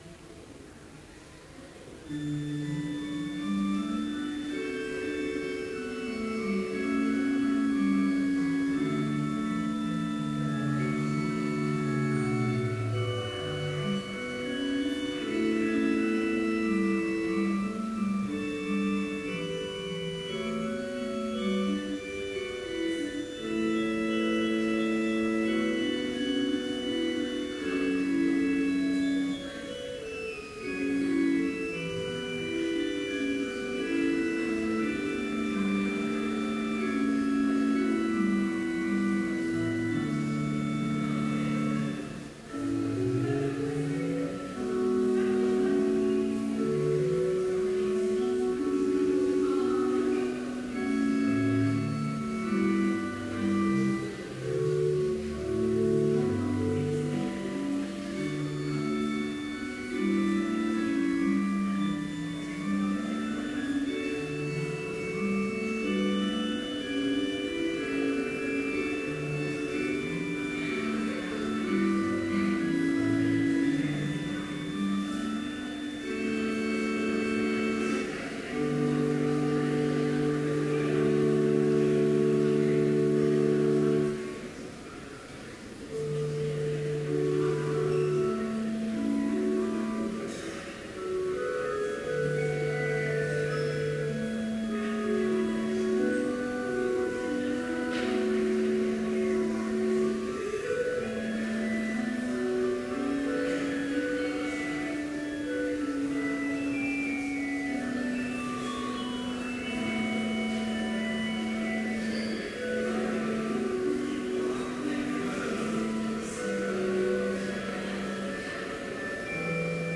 Complete service audio for Chapel - May 7, 2015